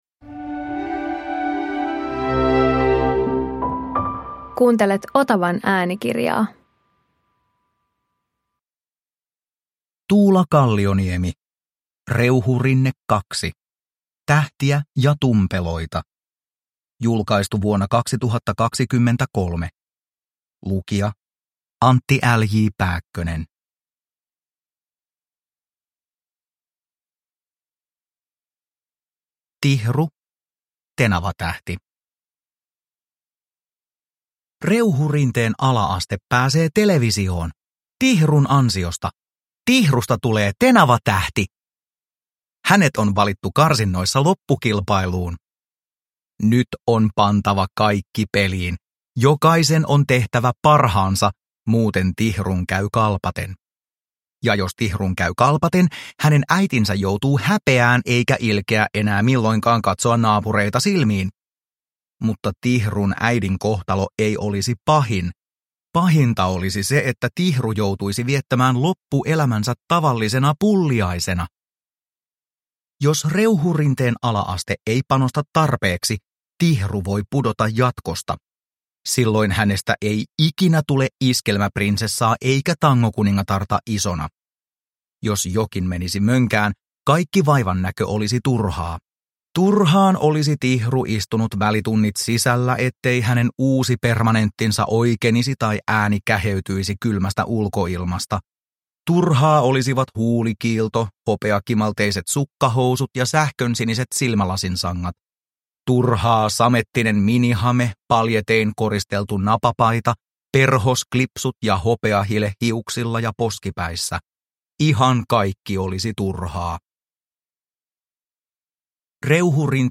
Tähtiä ja tumpeloita – Ljudbok – Laddas ner